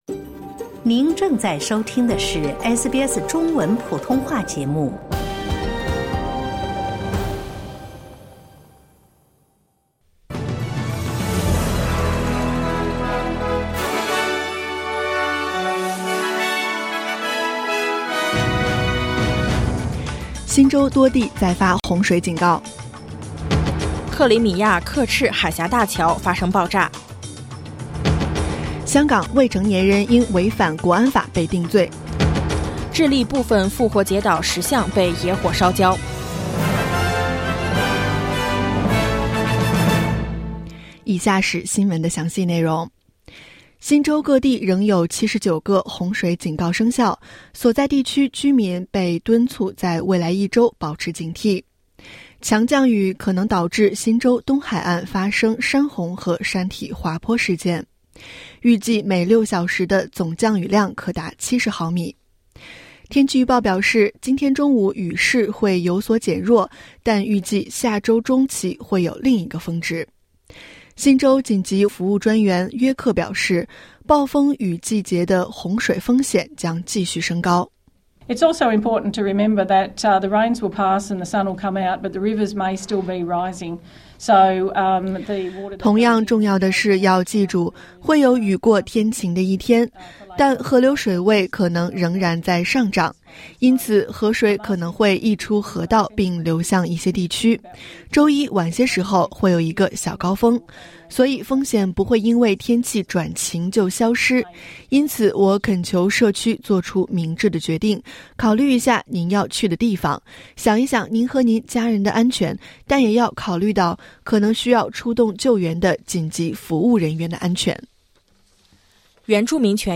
SBS早新闻（2022年10月9日）